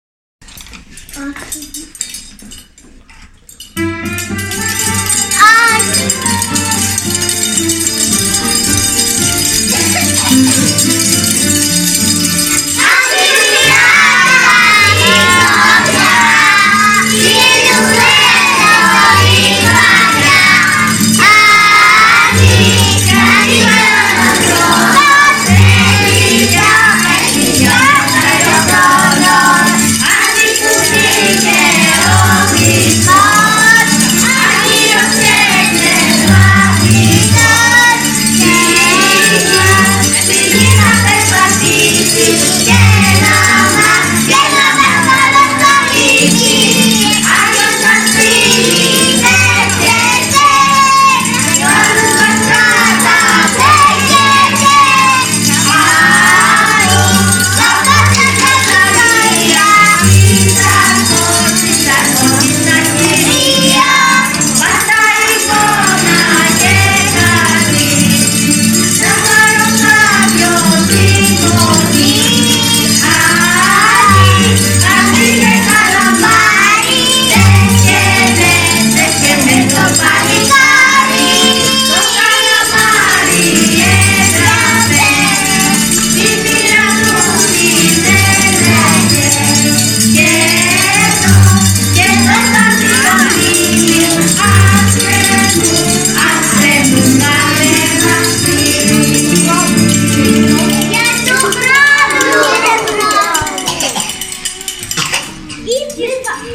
Τα παιδιά τραγουδούν τα κάλαντα και σας στέλνουν μελωδικές ευχές…..
κάλαντα.mp3